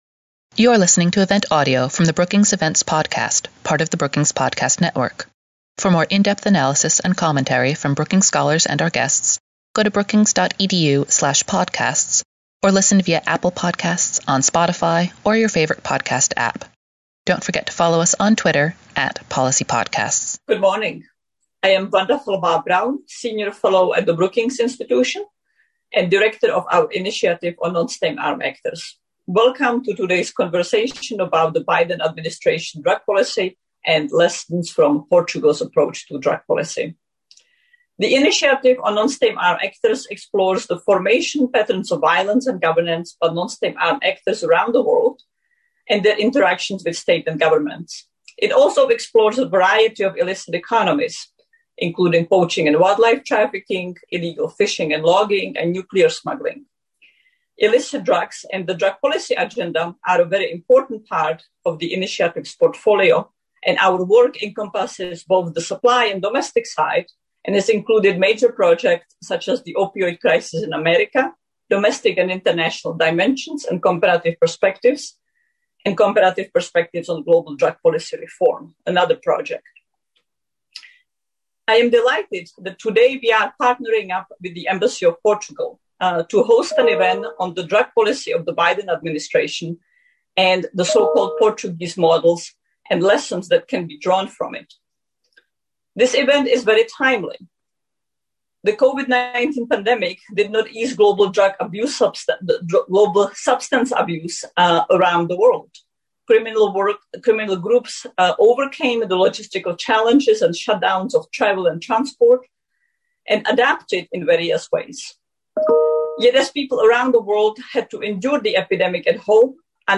After their remarks, panelist took questions from the audience.